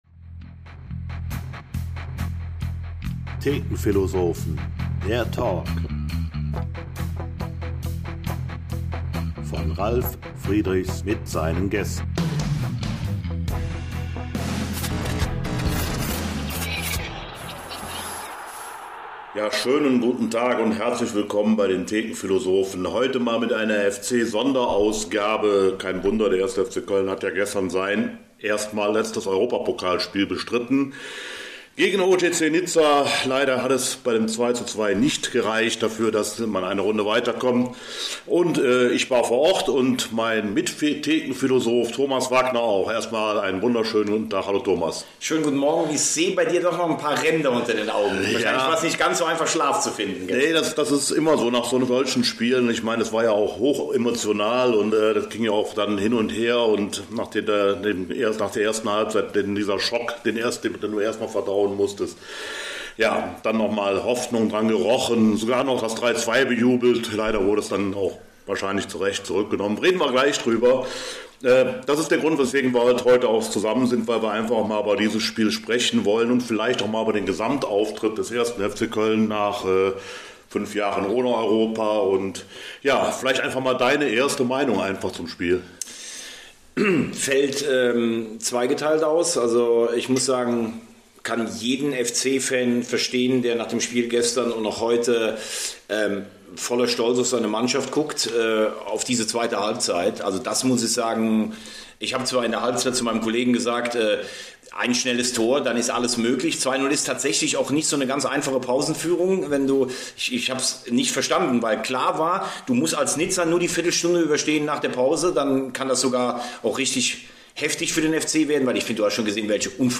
Thekenphilosophen - Das Aus für den 1. FC Köln gegen OGC Nizza - Folge 15 ~ FC-Thekenphilosophen - Der Talk Podcast